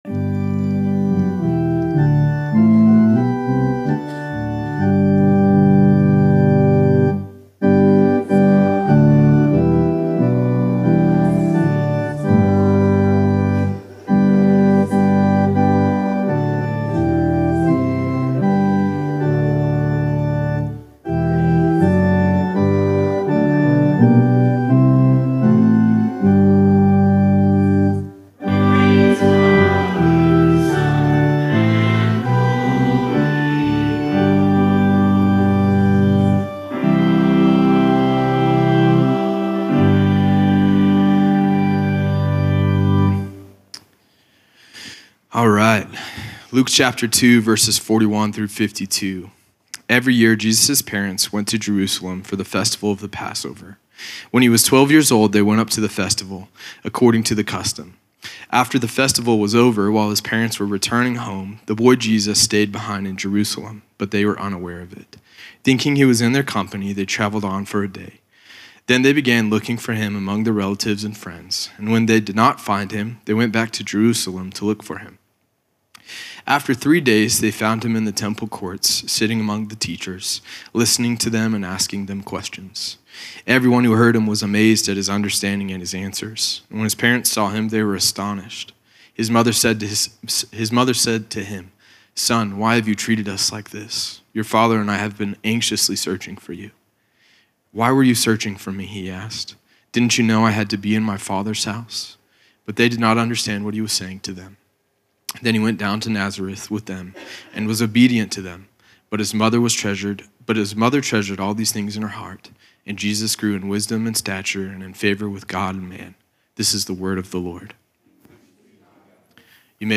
sermon audio 1228.mp3